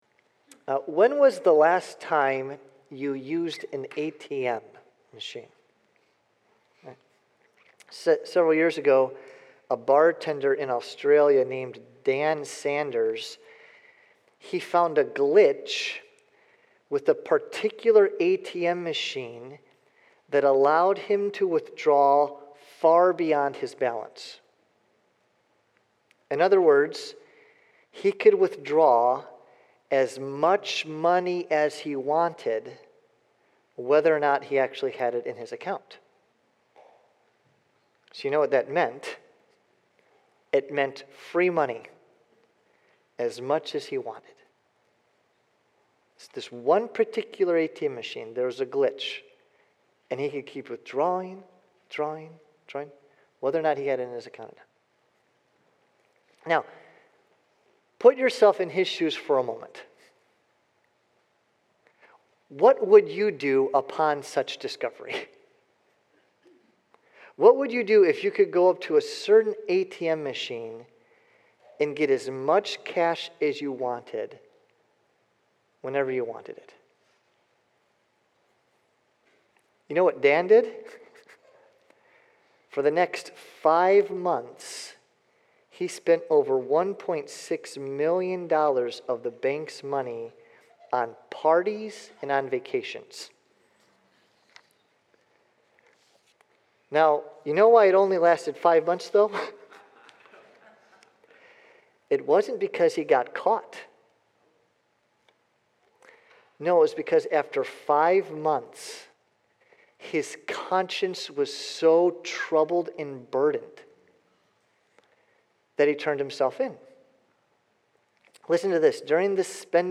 Sermon audio from Faith Community Church in Prospect, KY.